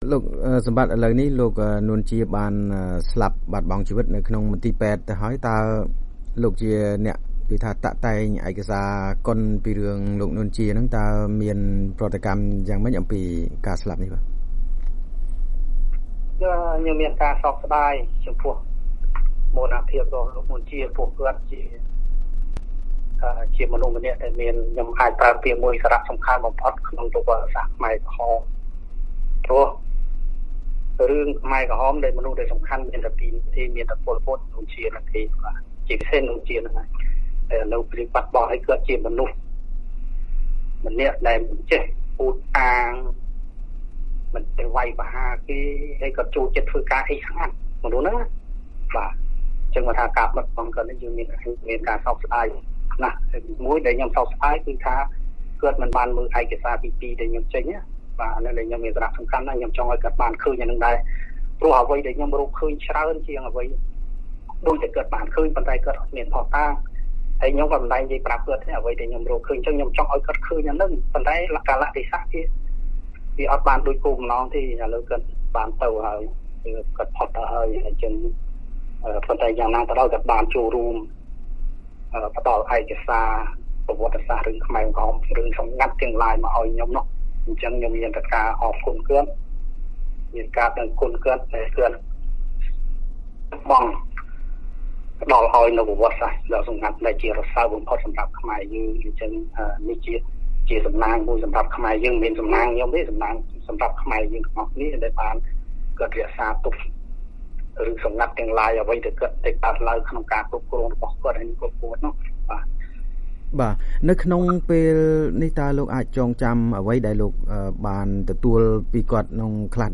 បទសម្ភាសន៍
តាមទូរសព្ទ